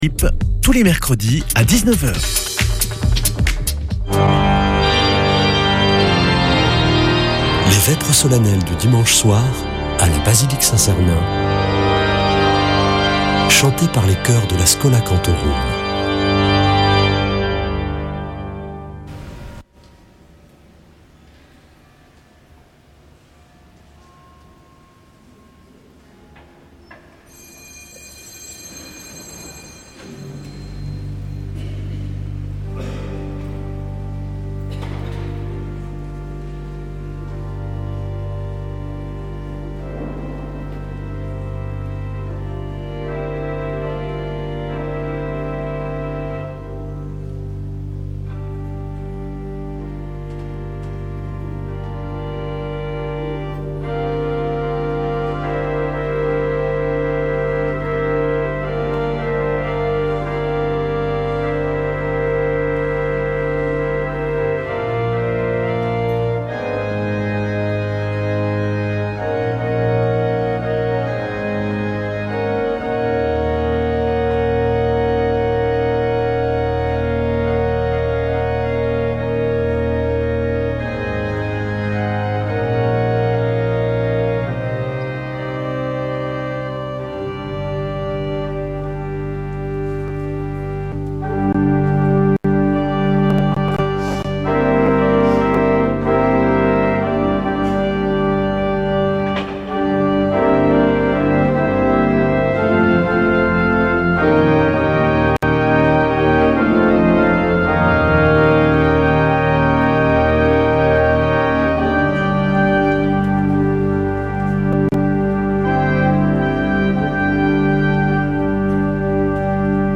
Vêpres de Saint Sernin du 05 oct.